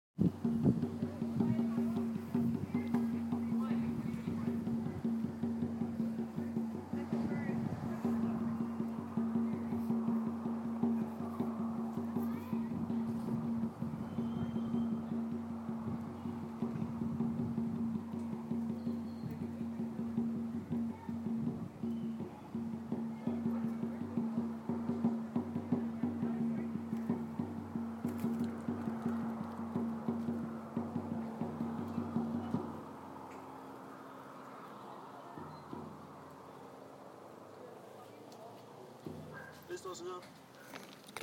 drums at la gruta